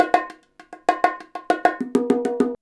100BONG08.wav